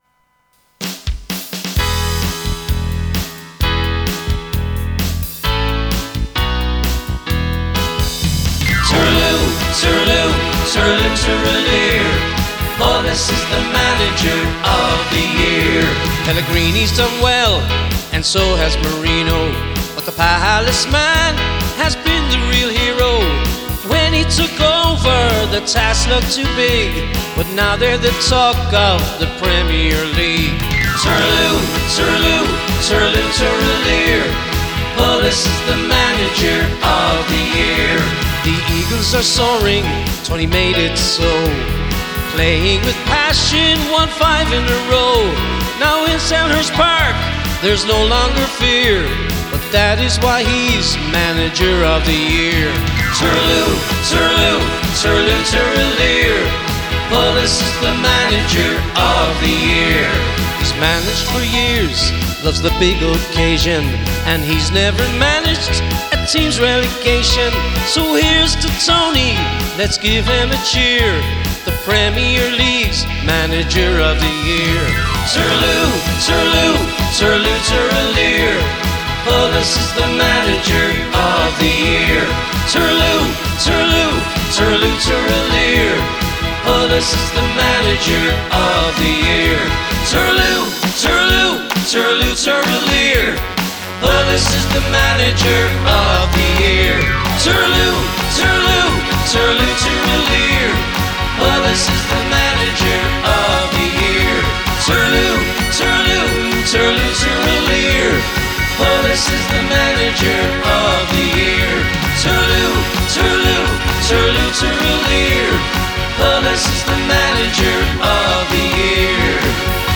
a jolly little number
Guitarist